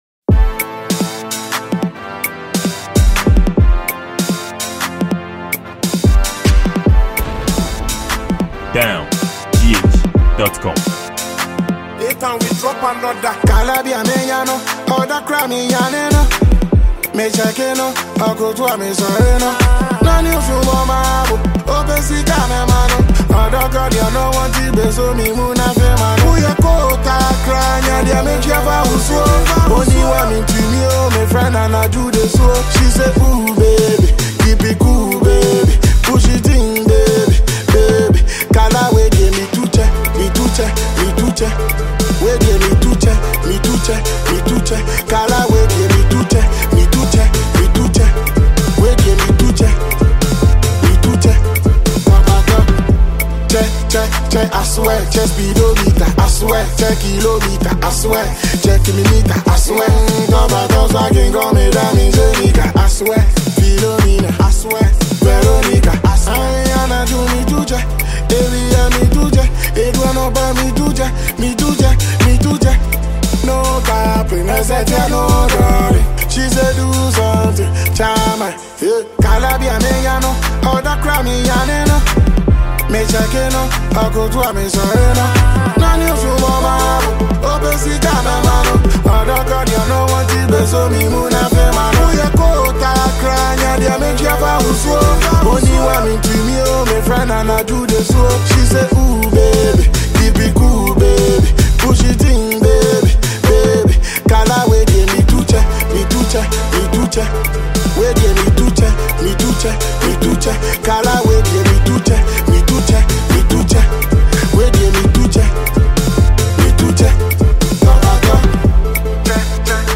Ghana Music